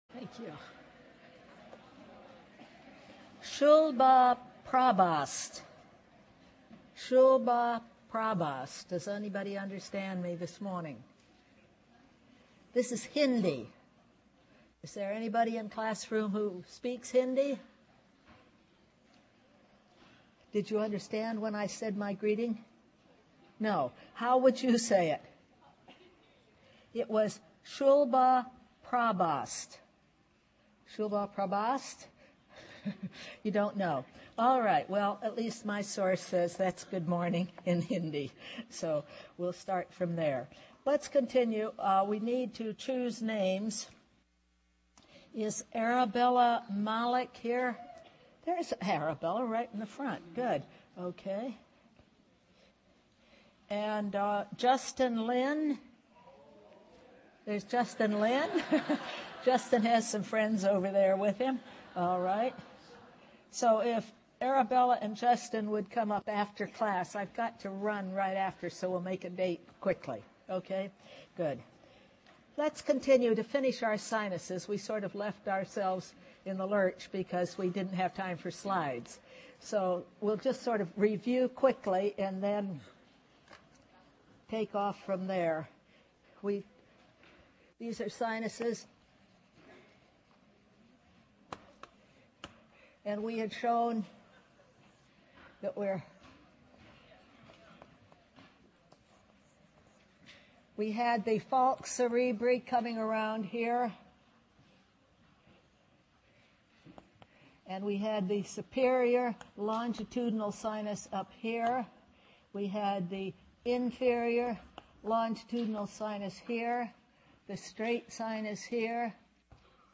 Lymphatic System Audio Lecture